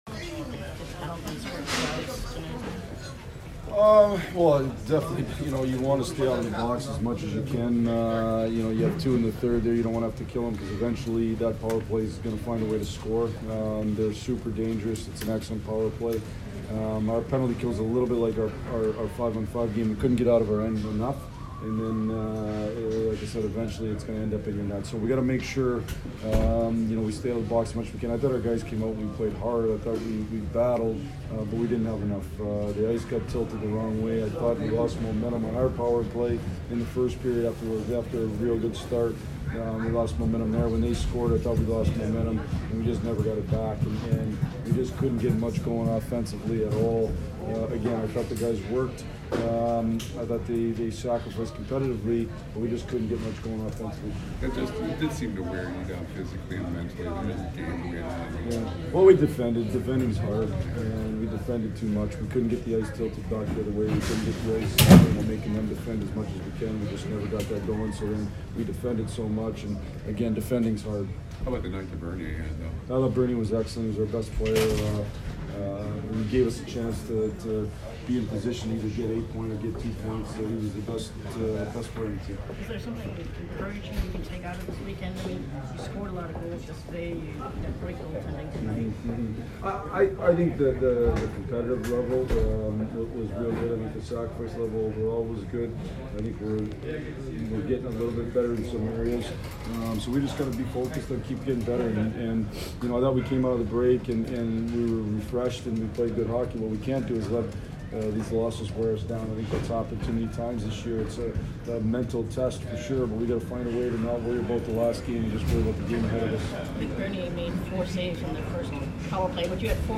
Blashill post-game 12/29